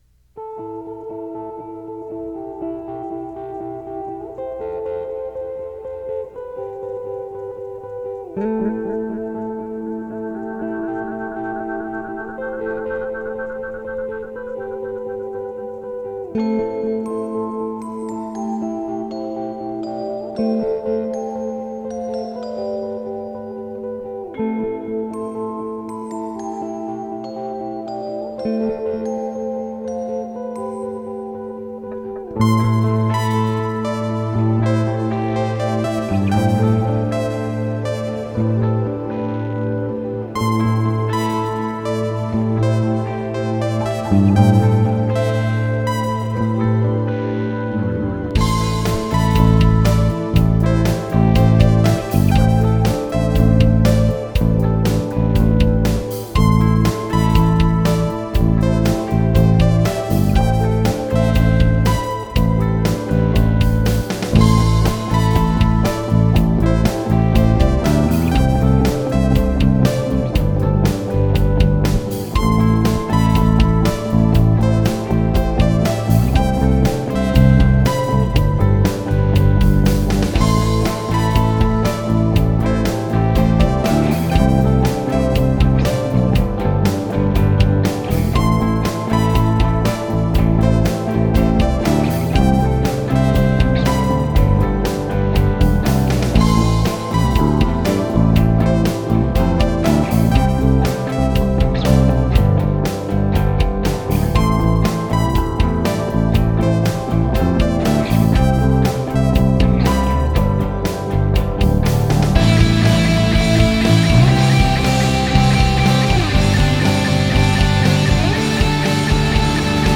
I’m really happy with how this one turned out considering it’s a really rough cut (for the bass track anyway).